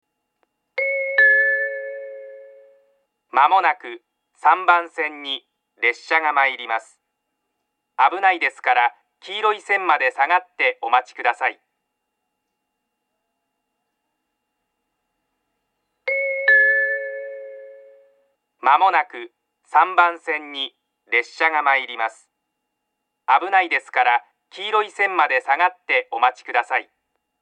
３番線接近放送